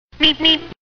road-runner-meep.mp3